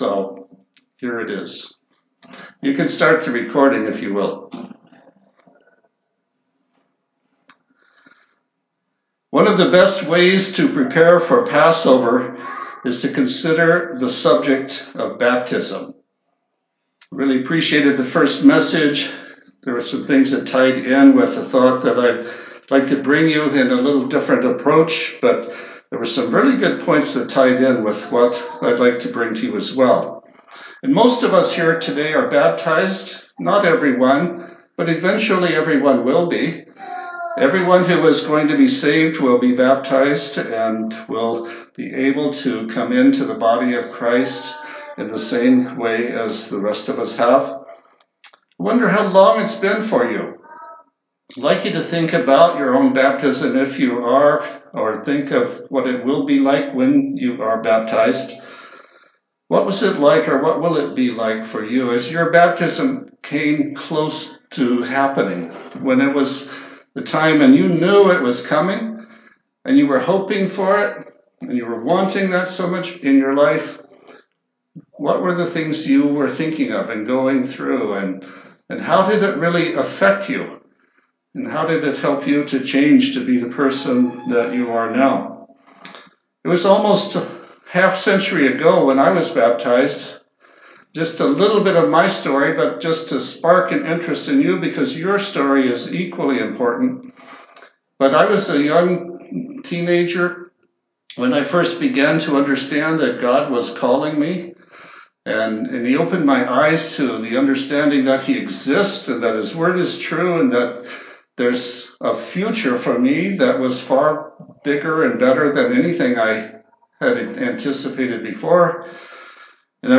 He spoke about and prayed about and prepared for His baptism--not His first, but His second baptism. This sermon reflects on some of the direct Scriptures that show us this striking correlation between baptism and Jesus' own death, burial and resurrection.